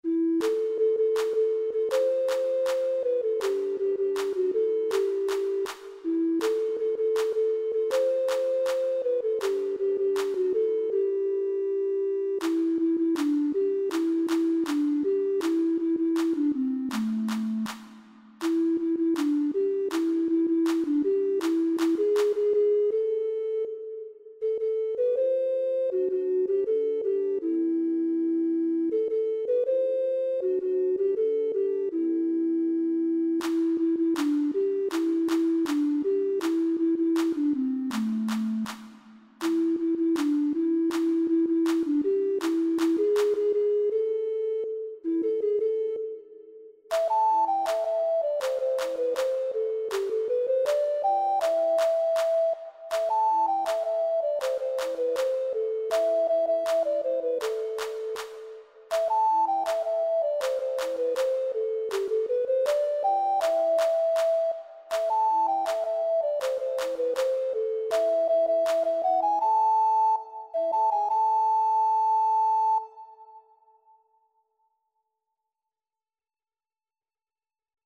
1ª Voz